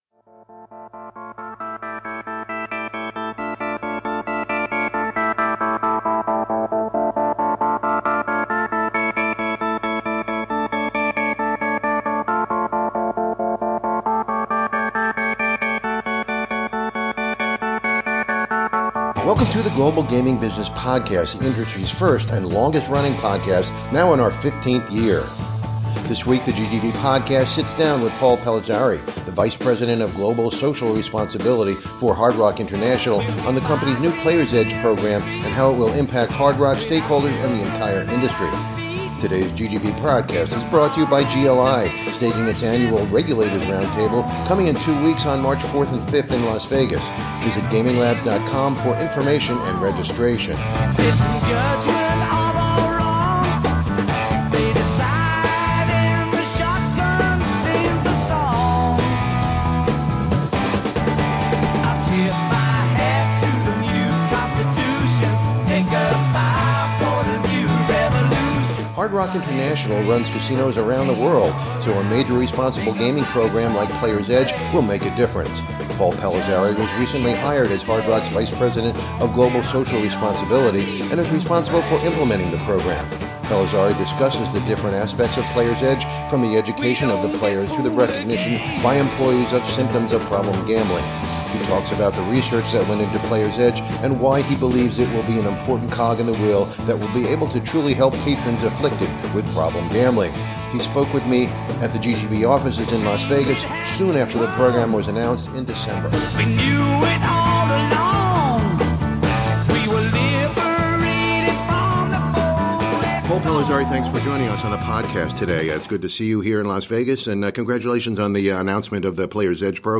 at the GGB offices in Las Vegas soon after the program was announced in December.